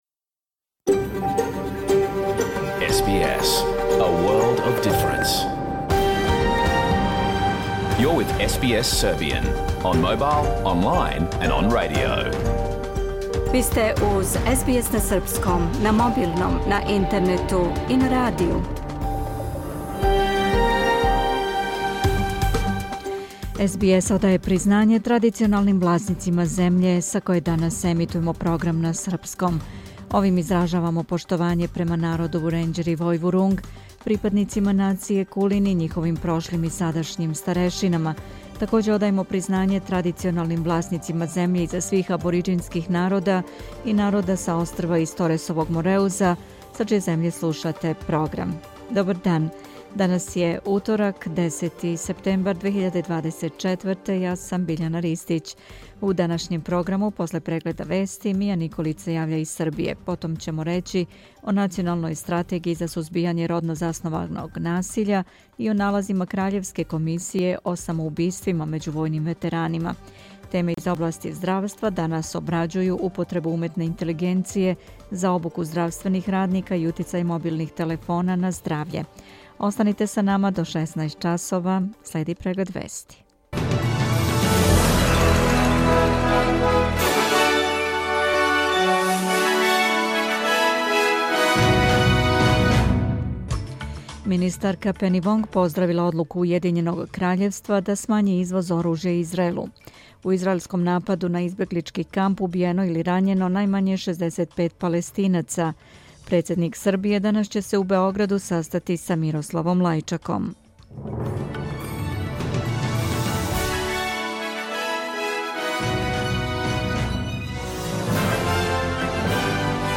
Програм емитован уживо 10. септембра 2024. године
Уколико сте пропустили данашњу емисију, можете је послушати у целини као подкаст, без реклама.